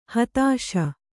♪ hatāśa